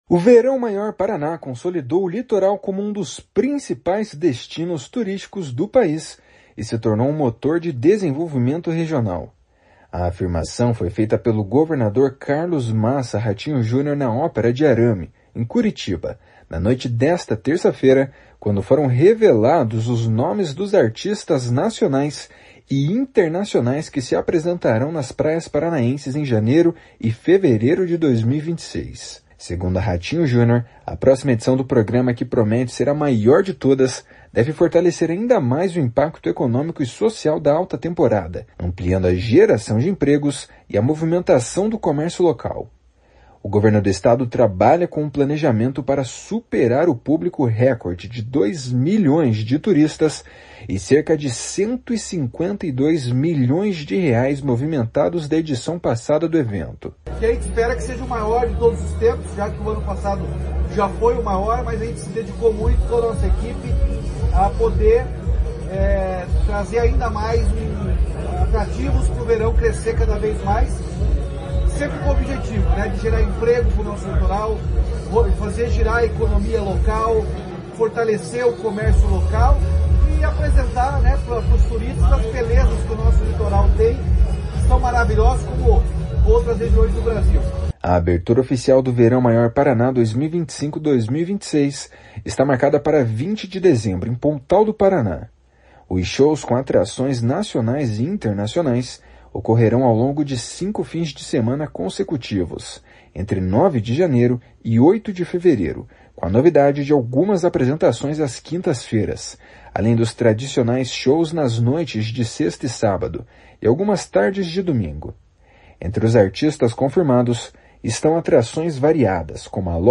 // SONORA RATINHO JUNIOR //
// SONORA HÉLIO WIRBISKI //